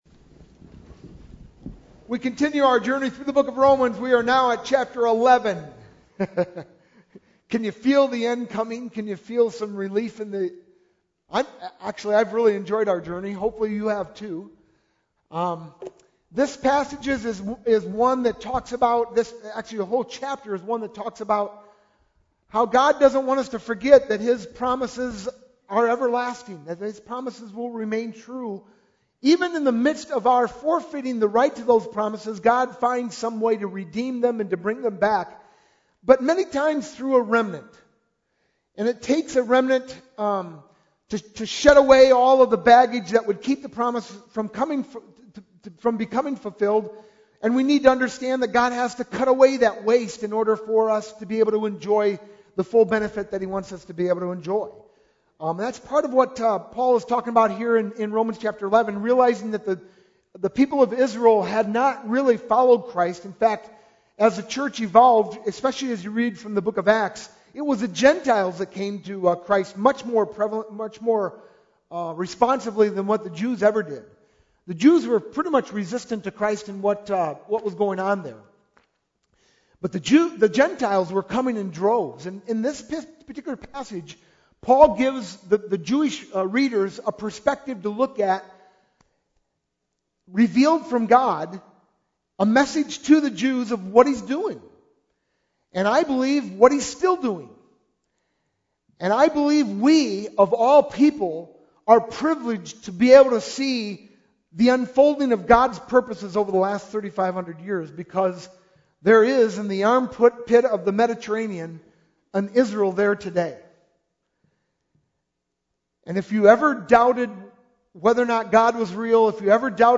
sermon-9-25-11.mp3